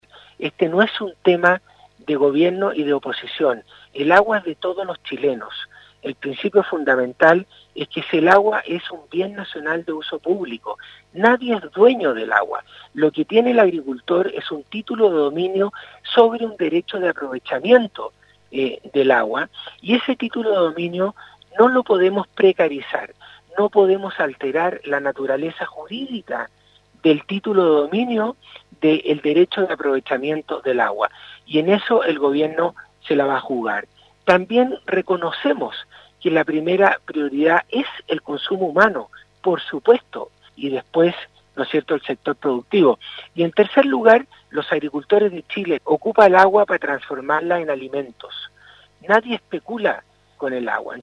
Un llamado a los parlamentarios de regiones agrícolas para no politizar la discusión de la reforma al código de aguas, realizó el Ministro de Agricultura Antonio Walker en diálogo con Radio SAGO. El secretario de Estado indicó que el tema del agua es  técnico y el código es un instrumento clave para el avance de la agricultura, por lo que se requería de un acuerdo nacional en la materia.